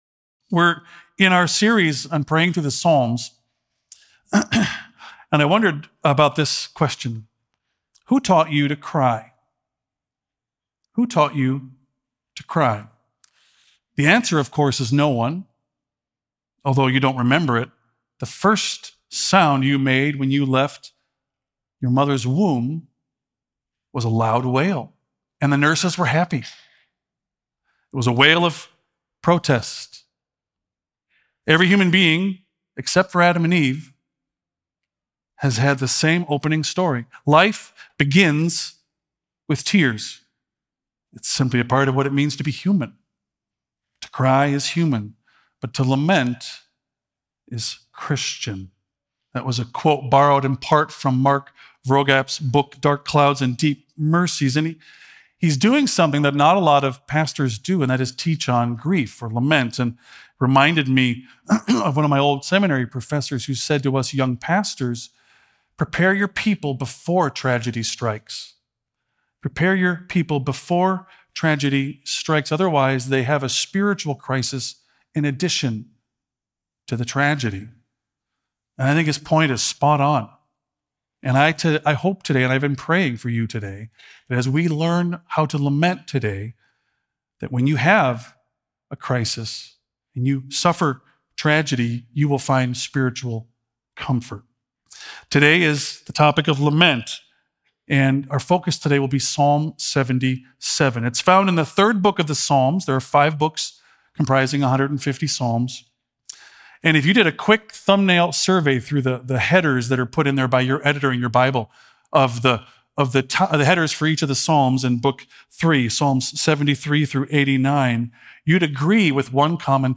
Palm Sunday Sermon